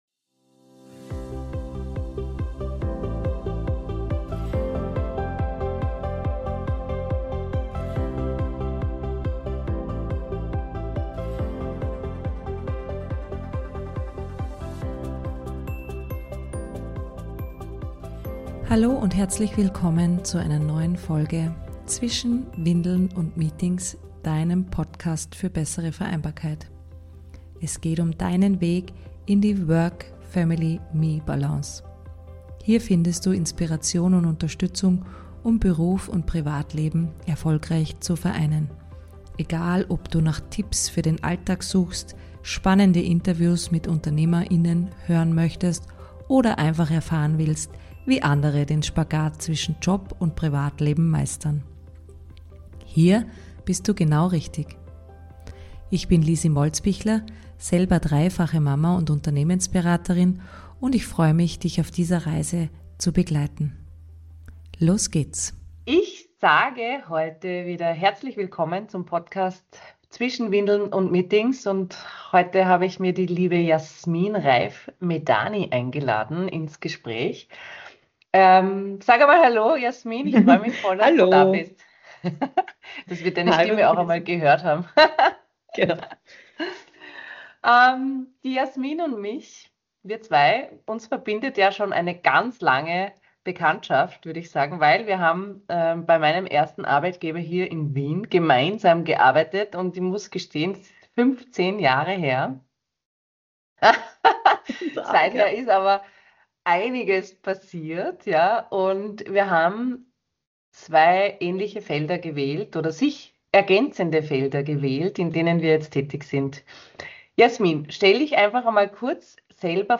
Mental Load und Selbstfürsorge: Ein Gespräch